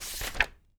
TURN PAGE1-S.WAV